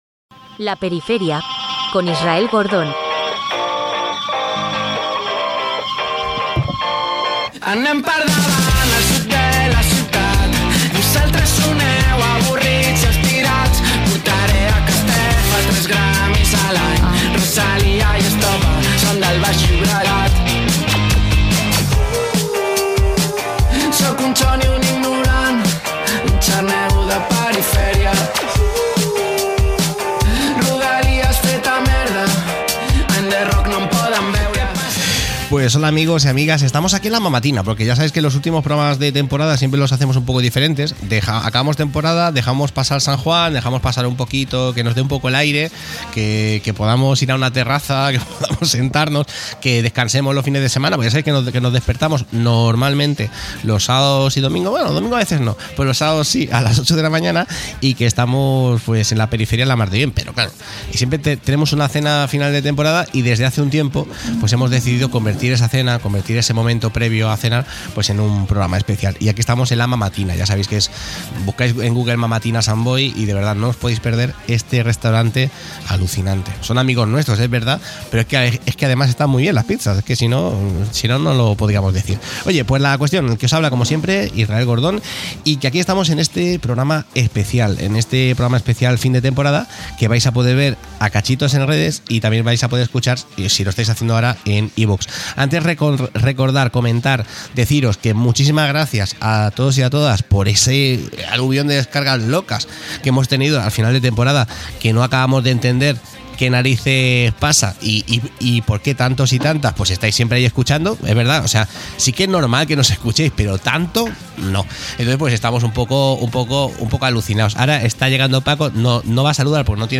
66e02e6b170aff312afdfc84db774f1a2c636b46.mp3 Títol Ràdio Sant Boi Emissora Ràdio Sant Boi Titularitat Pública municipal Nom programa La periferia Descripció Careta del programa, programa final de temporada fet des del restaurant La Mamatina de Sant Boi. Els integrants del programa comenten com passen l'estiu i recorden alguns dels millors moments del programa i com es fan algunes de les veus de les paròdies